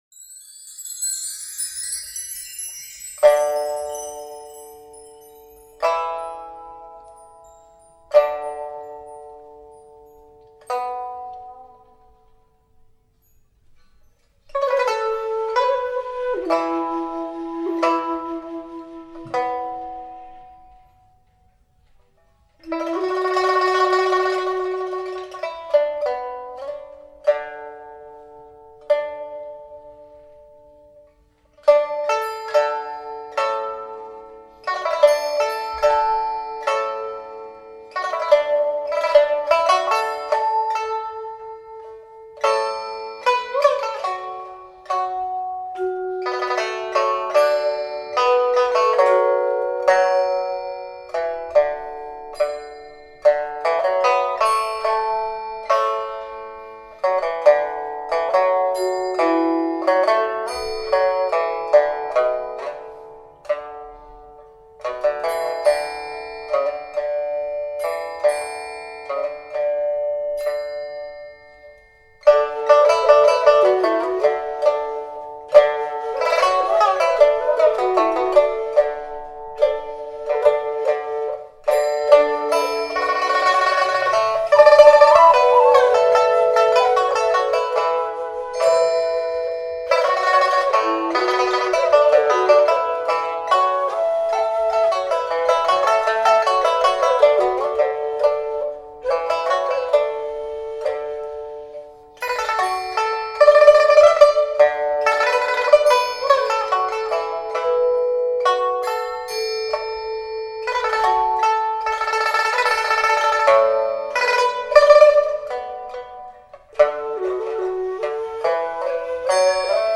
琵琶